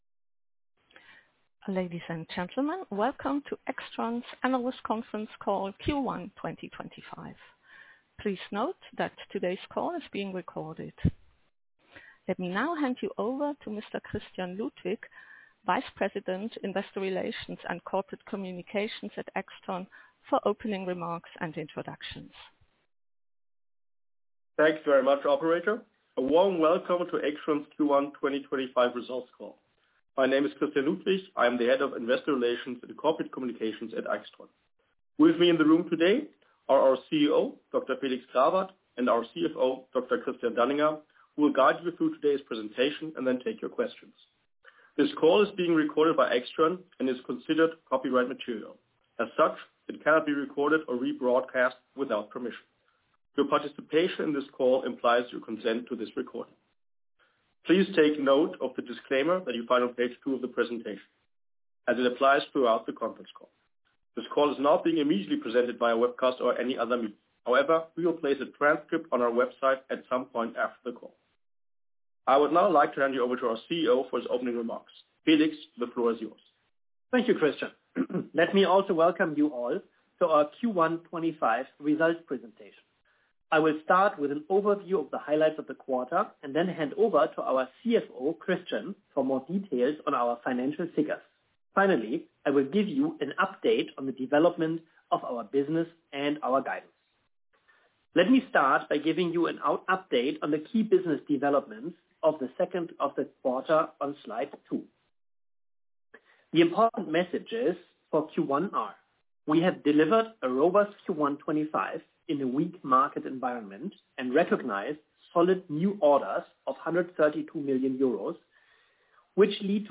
im Rahmen der Veröffentlichung der Mitteilung für das H1/2025 Ergebnis hielt AIXTRON am Donnerstag, den 31.07.2025, um 15:00 (MESZ), 06:00 (PDT), 09:00 (EDT) eine Telefonkonferenz (in englischer Sprache) für Analysten und Investoren ab.